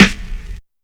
• Long Room Reverb Acoustic Snare Sound D# Key 46.wav
Royality free snare drum tuned to the D# note. Loudest frequency: 1502Hz
long-room-reverb-acoustic-snare-sound-d-sharp-key-46-UDQ.wav